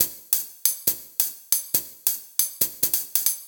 Vaux Hats 138bpm.wav